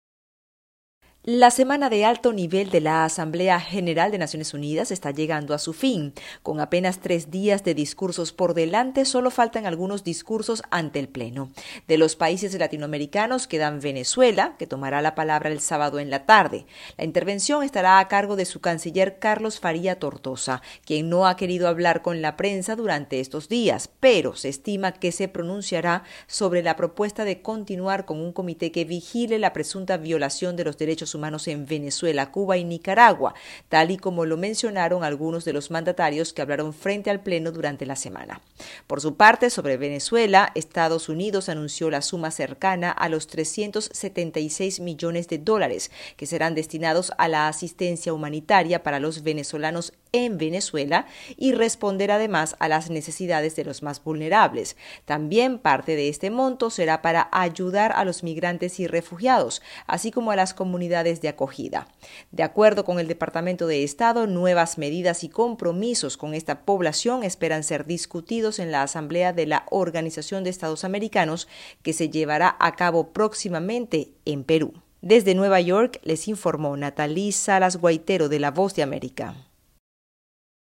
Hoy se cumple el cuarto día en el que se escucharán más de 30 discursos como sucedió en los días previos y los temas siguen siendo la guerra en Ucrania, la inseguridad alimentaria y el cambio climático. Desde la sede de la ONU en Nueva York